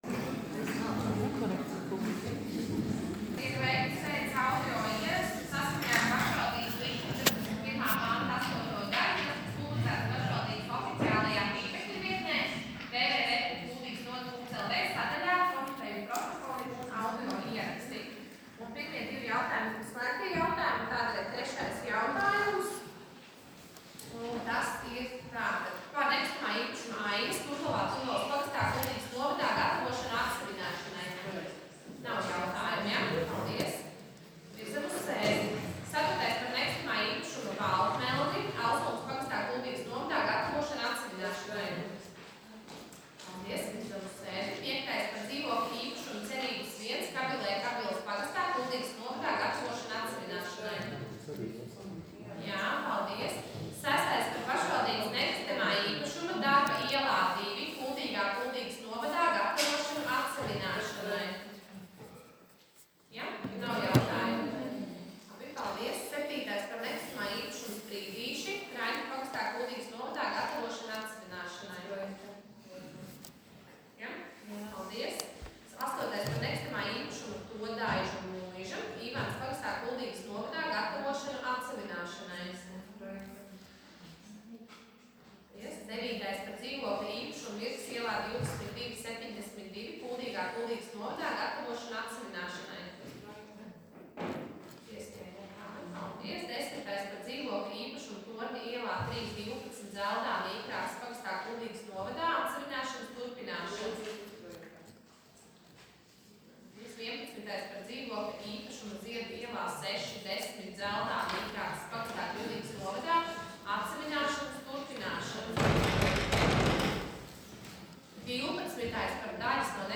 Attīstības komitejas sēdes audio ieraksts